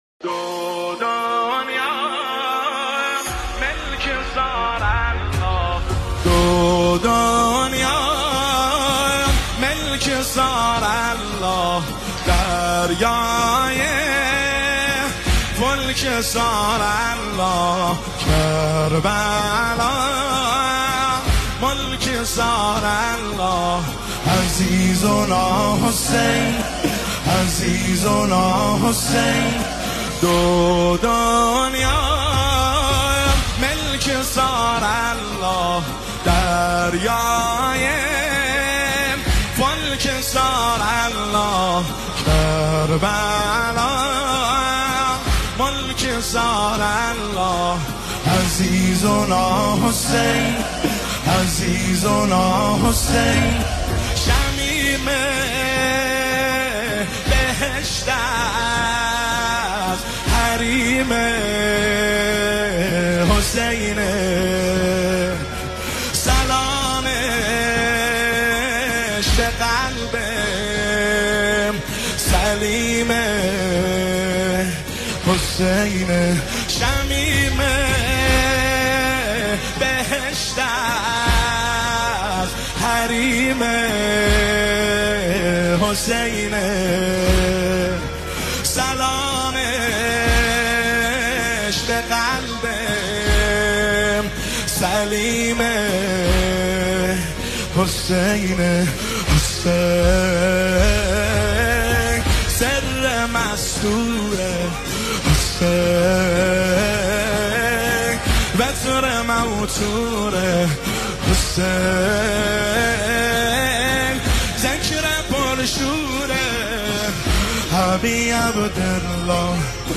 نماهنگ ، واحد  شهادت حضرت رقیه(س) 1404